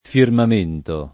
[ firmam % nto ]